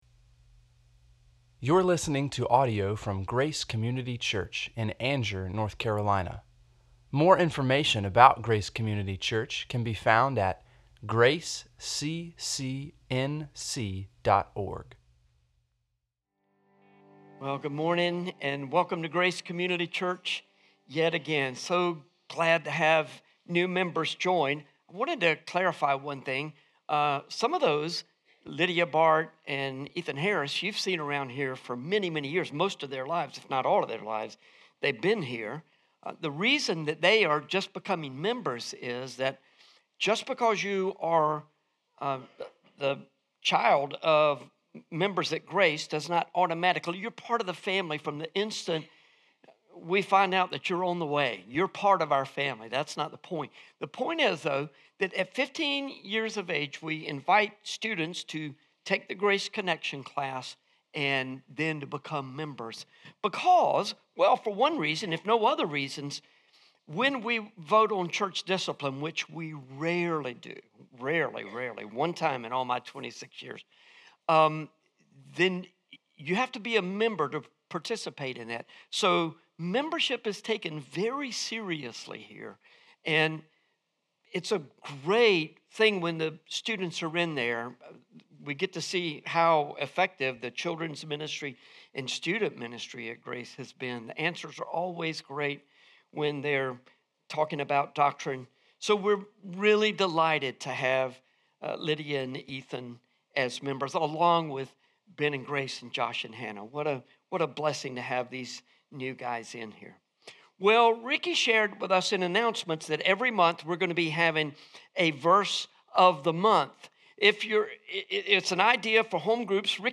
sermon-2-2-25.mp3